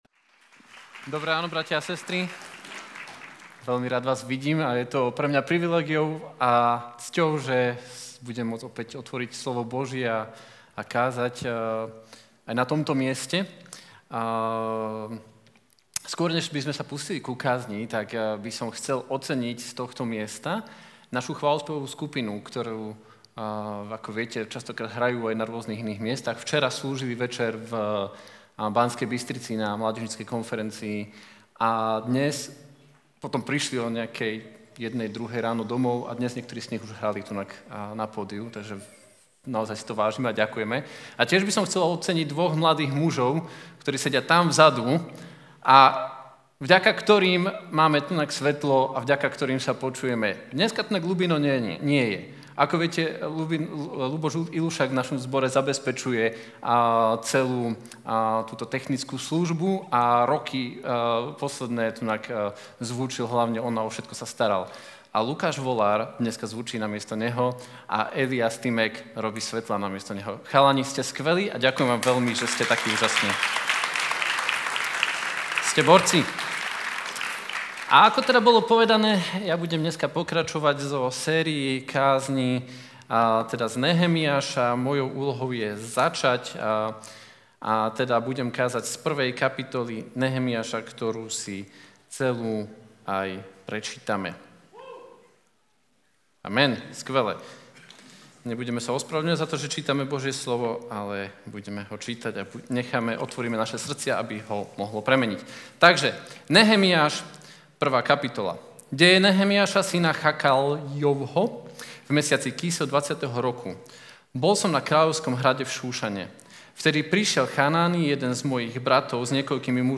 Vypočuj si túto kázeň a dovoľ Bohu, aby obnovil aj Tvoj život!